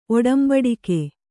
♪ oḍambaḍike